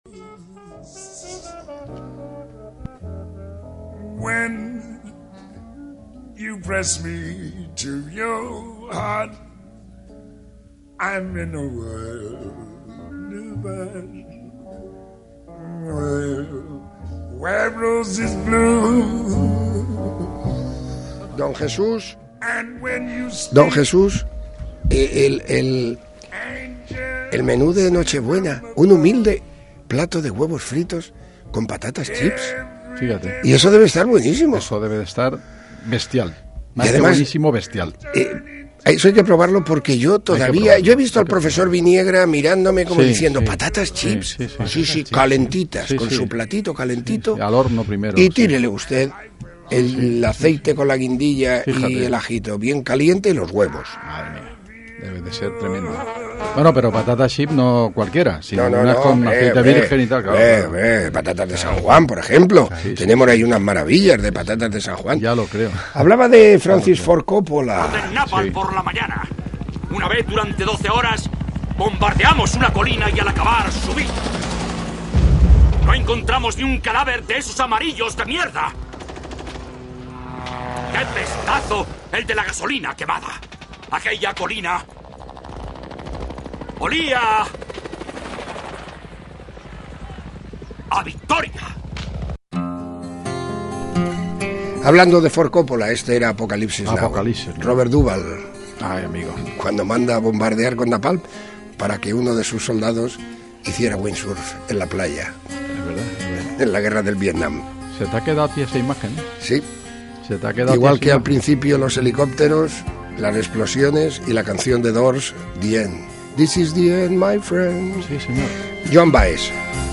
Magacín Especial Viernes TRES EN UNO